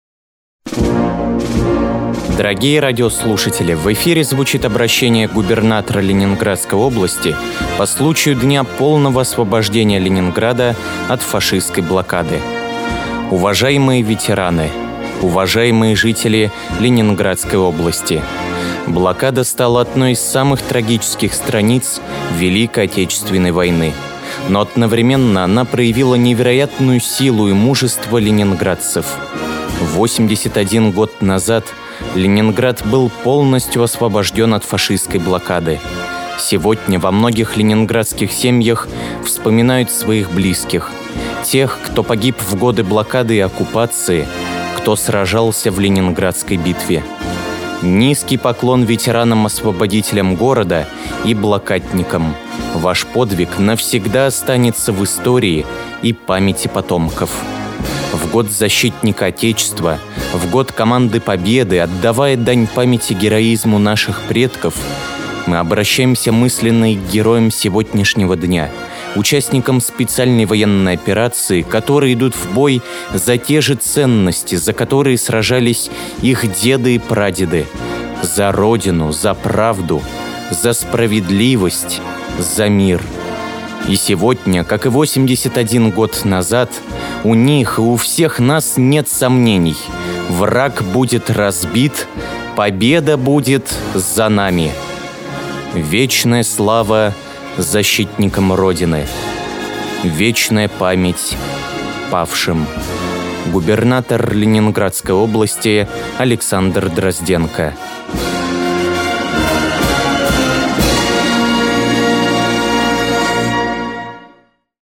Обращение Губернатора Ленобласти по случаю Дня полного освобождения Ленинграда от фашистской блокады
Губернатор Ленинградской области Александр Дрозденко